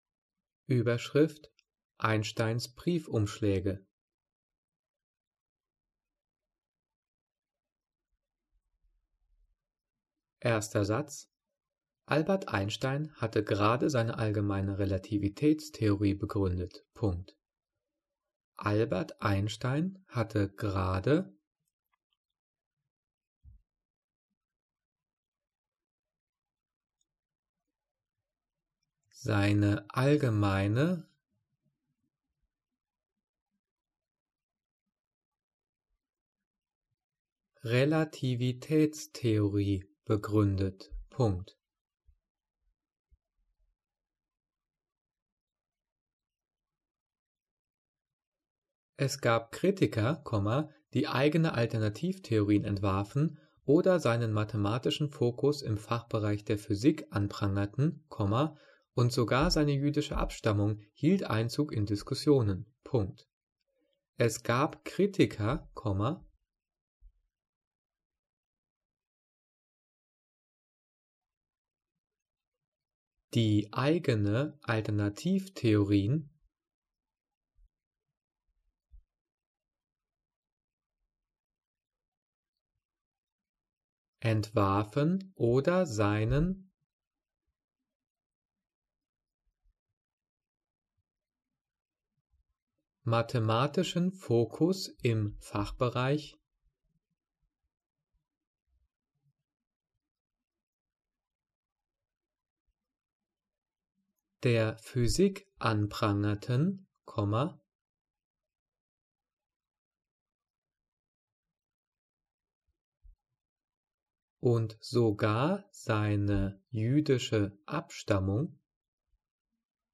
Diktat: "Einsteins Briefumschläge" - 7./8. Klasse - Dehnung und Schärfung
Die vielen Sprechpausen sind dafür da, dass du die Audio-Datei pausierst, um mitzukommen.
Diktiert: